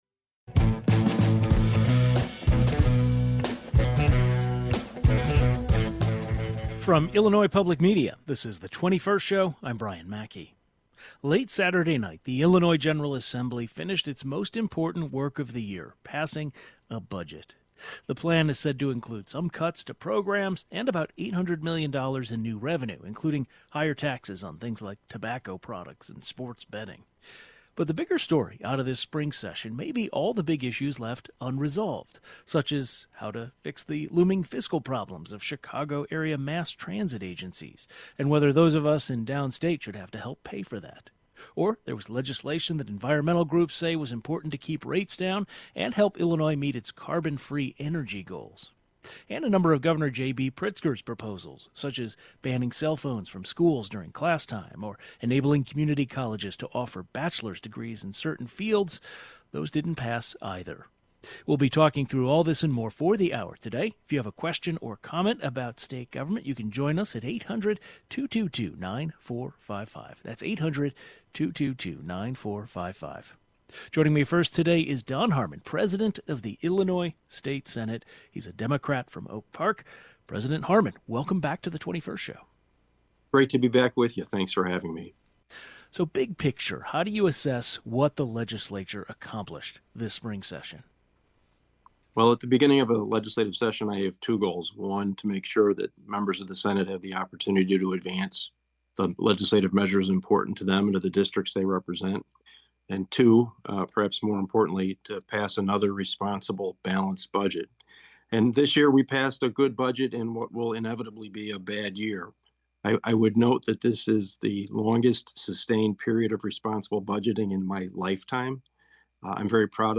GUEST Don Harmon President of the Illinois State Senate Tags